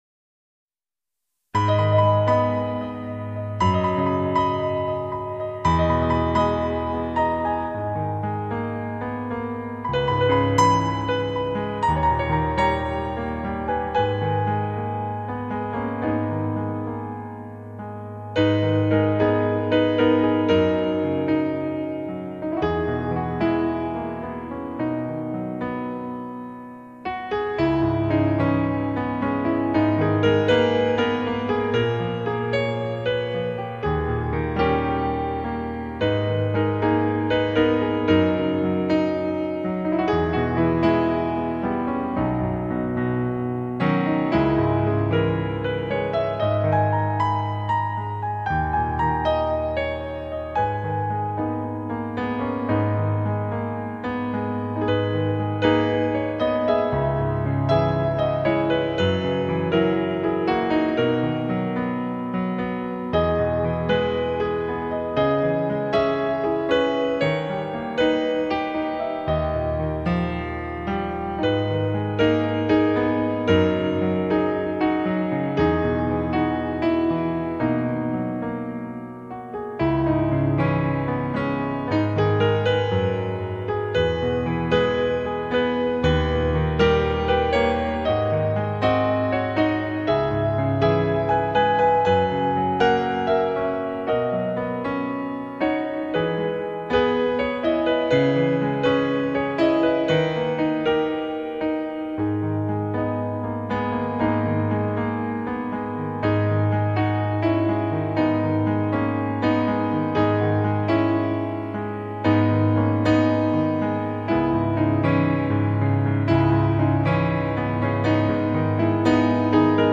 音乐类别：New Age, Easy Listening, Instrumental Pop
作品除了过去熟悉的轻柔、浪漫风格以外，更多了一点悠然閒散的味道。
奏出柔美中带著几分清新的琴声，